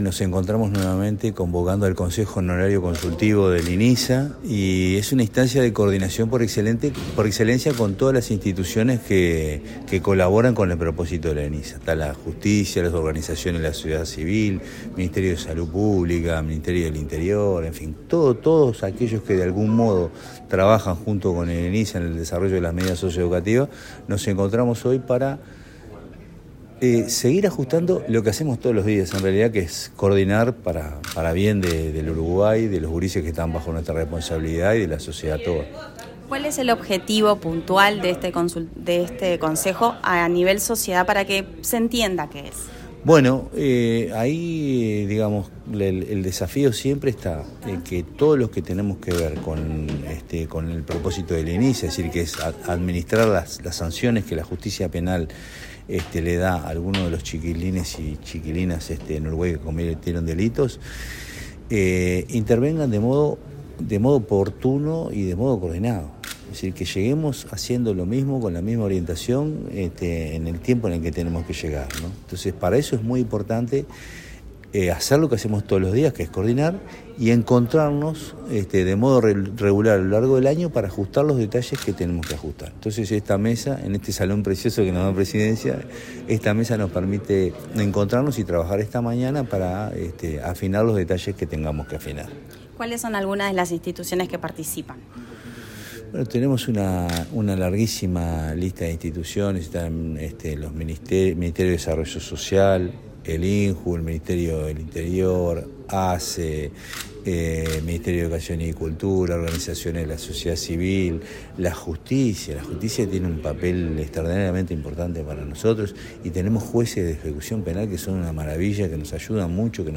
Declaraciones del presidente de INISA, Jaime Saavedra
Durante la apertura de la primera sesión del Consejo Nacional Consultivo Honorario 2025, el presidente del Instituto Nacional de Inclusión Social Adolescente (INISA), Jaime Saavedra, destacó la importancia de fortalecer la coordinación interinstitucional para mejorar la implementación de las medidas socioeducativas y avanzar en una gestión más articulada y eficaz.